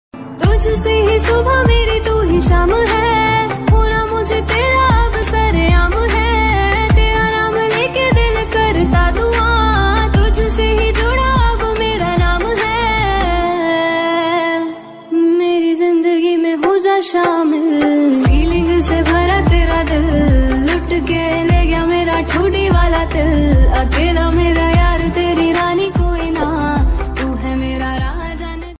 Female Version Ringtones Girl Voice Ringtones